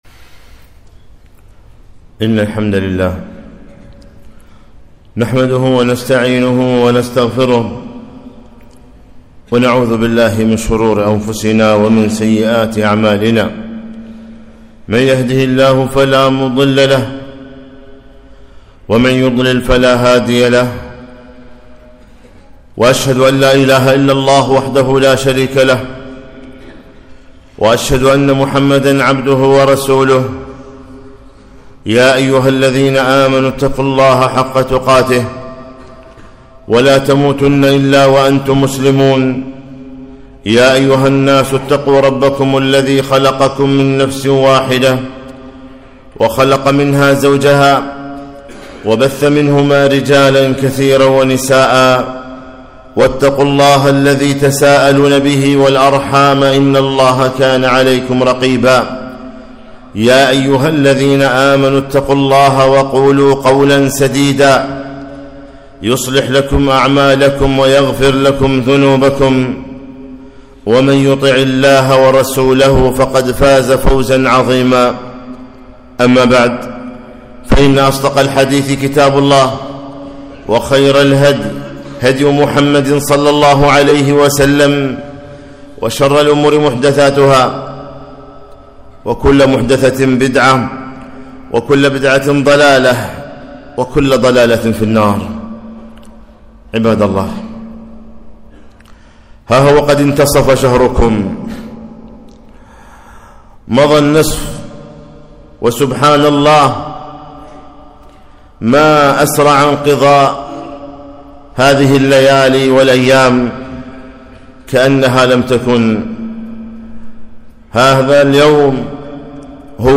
خطبة - وانتصف شهر رمضان